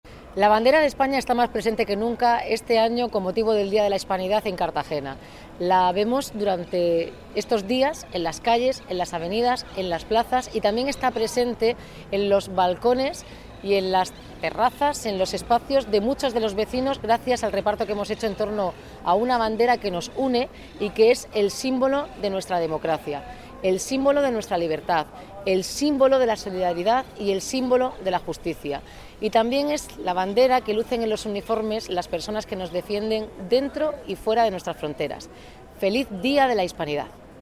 Enlace a Declaraciones de Noelia Arroyo